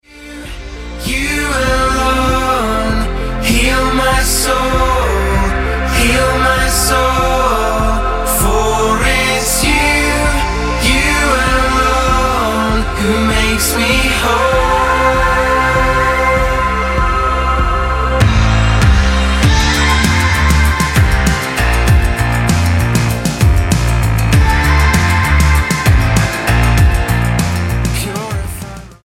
STYLE: Pop
dance-tinged stomper